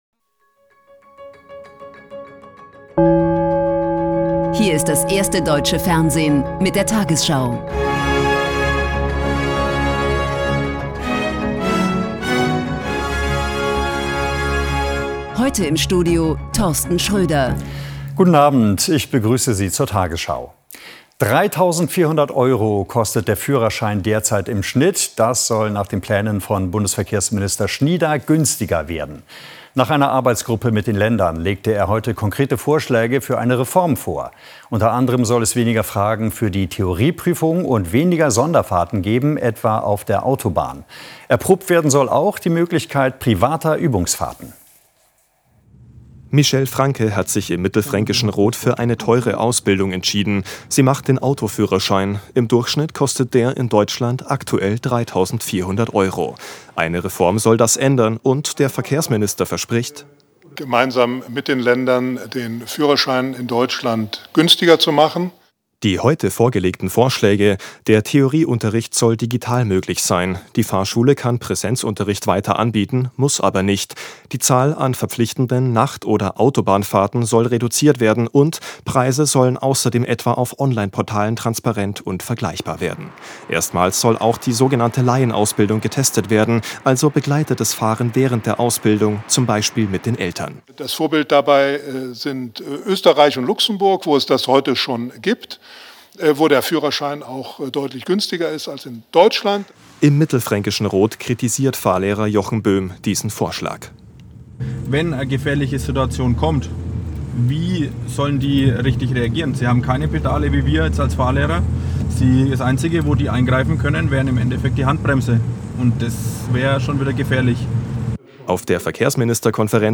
tagesschau 20:00 Uhr, 11.02.2026 ~ tagesschau: Die 20 Uhr Nachrichten (Audio) Podcast